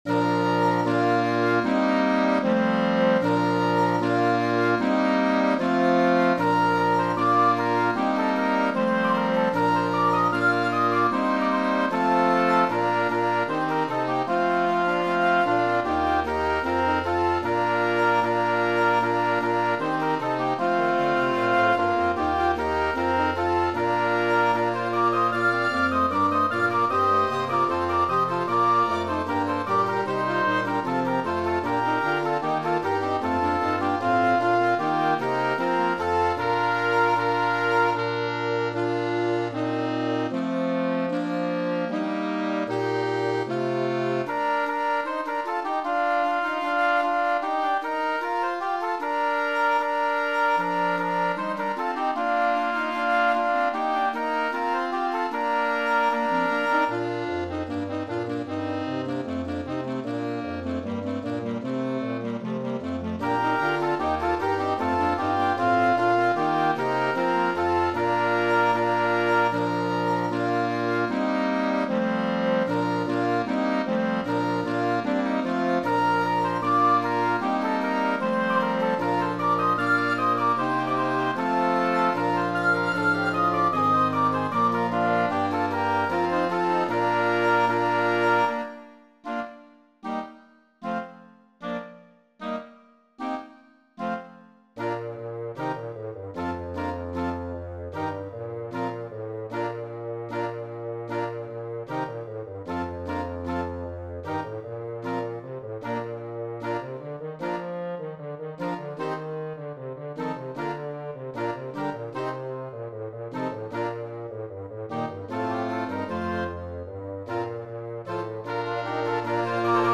Voicing: Woodwind Ensemble